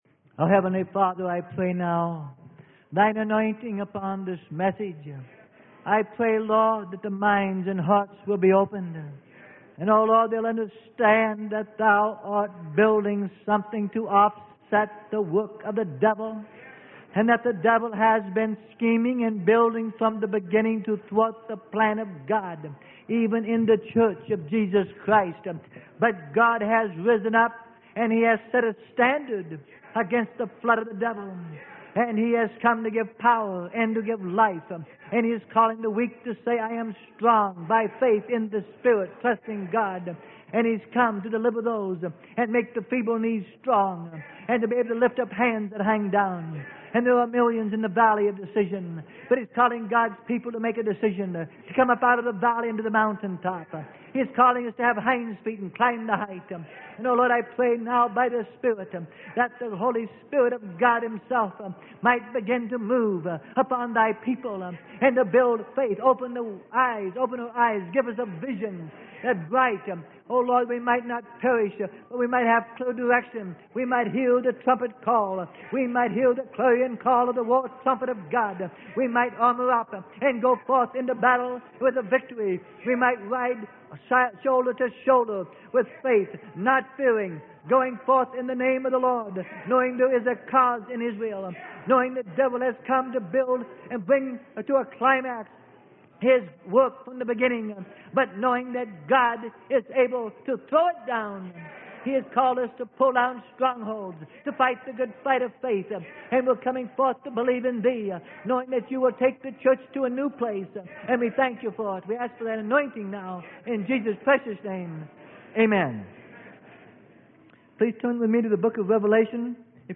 Sermon: THE ROAD TO WORLD GOVERNMENT.